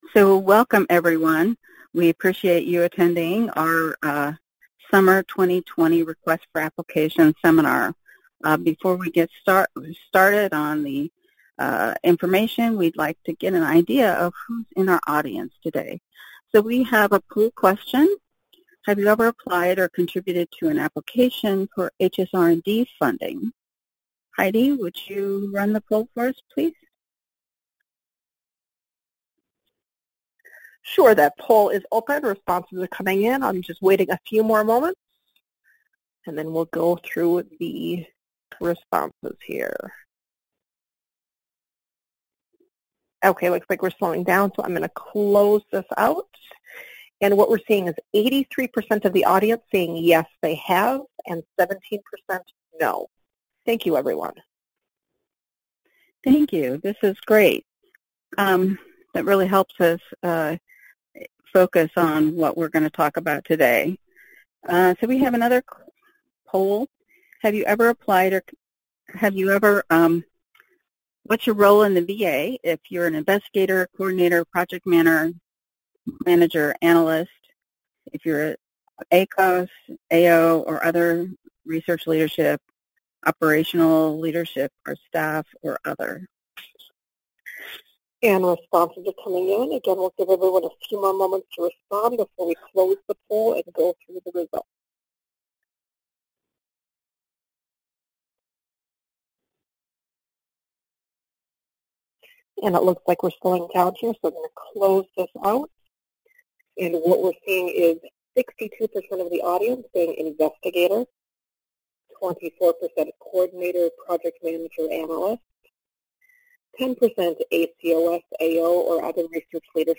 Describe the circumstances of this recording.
Description: The CyberSeminar will provide an overview of the HSR&D Request for Applications (RFAs) for the Summer 2020 review cycle. There will be an opportunity to answer questions, and the live session will be recorded and archived for on-demand access after the seminar.